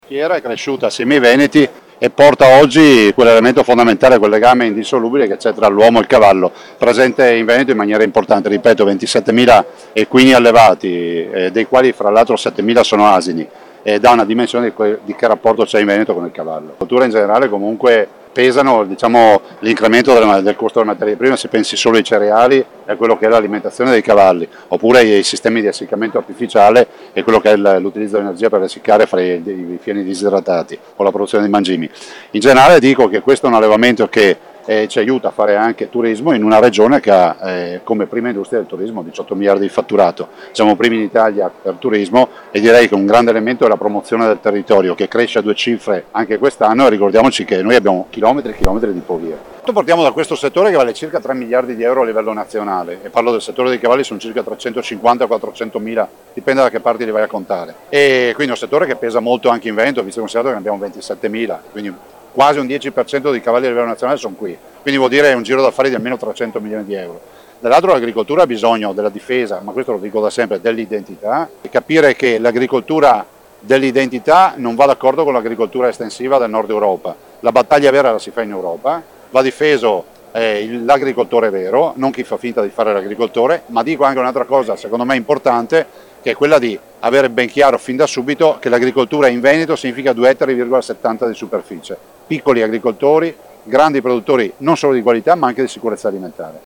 Presidente Regione Veneto, Luca Zaia:
Presidente-Regione-Veneto-Luca-Zaia-allinaugurazione-della-Fieracavalli.mp3